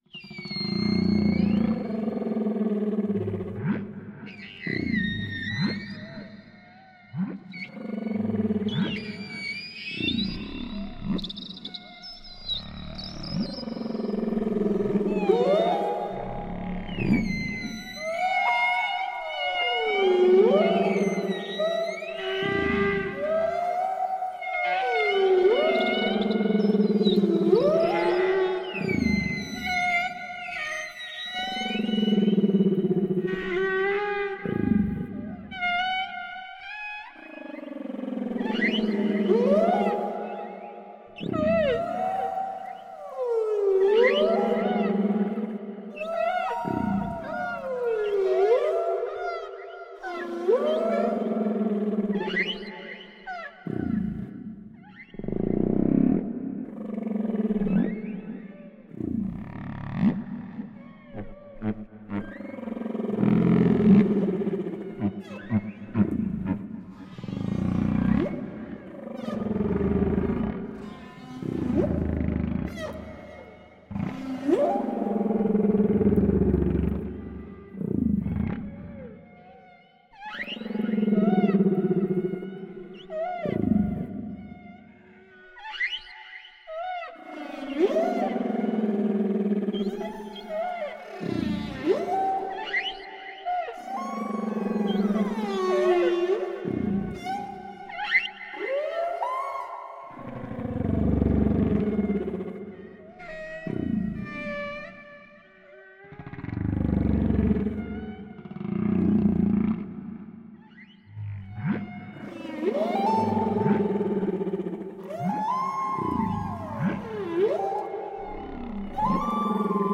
Songs_of_the_East_Australian_Humpback_Whales.mp3